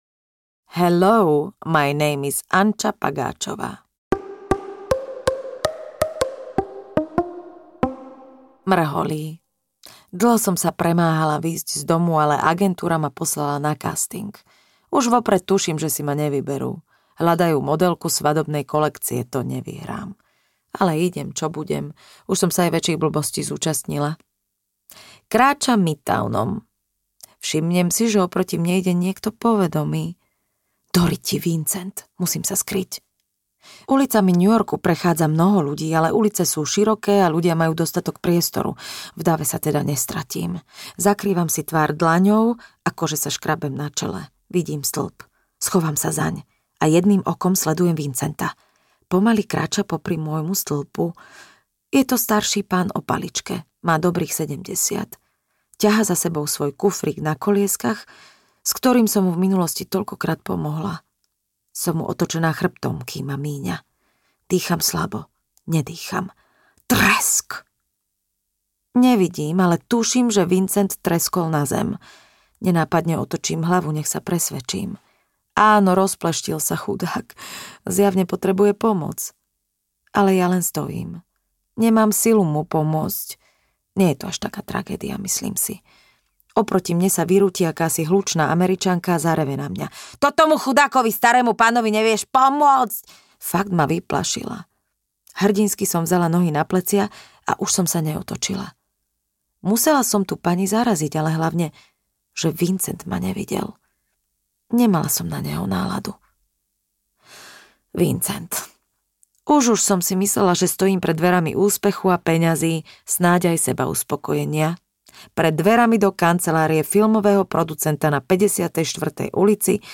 Ukázka z knihy
• InterpretLucia Siposová